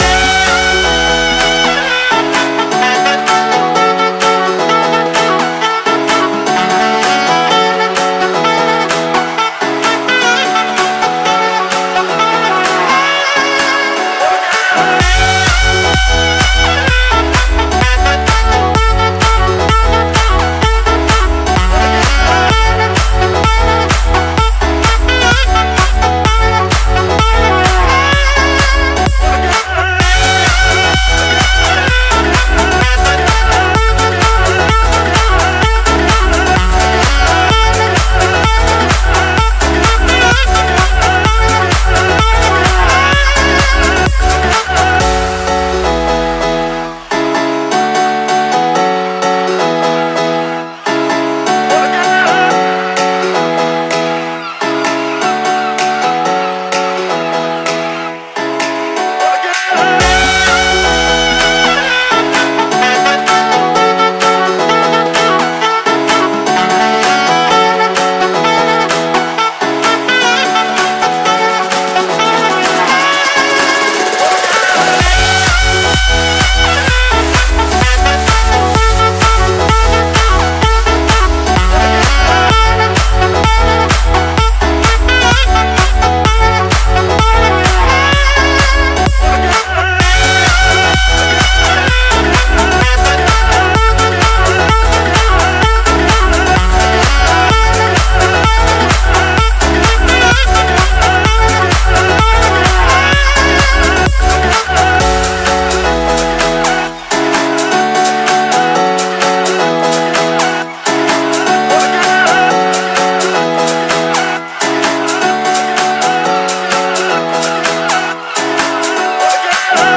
Saxophone
دانلود آهنگ بی کلام آرامش دهنده ساکسفون